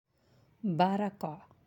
(barakah)